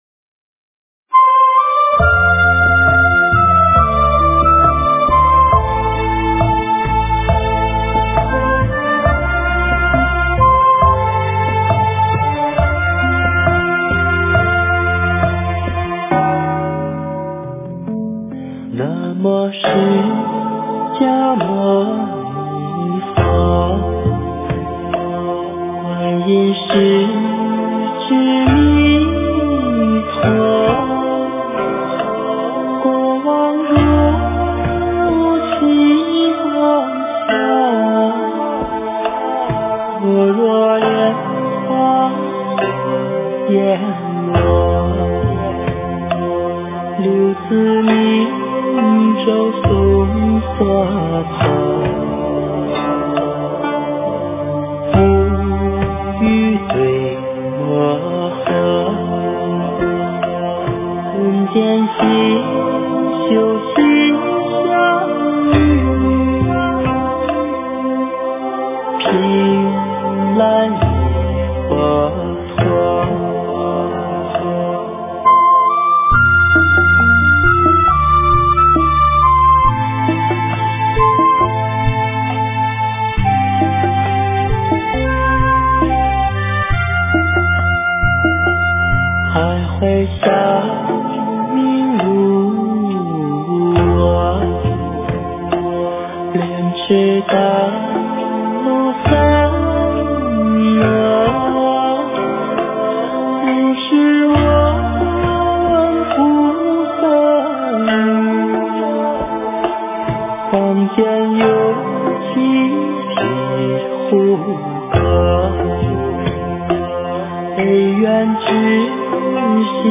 佛音
佛教音乐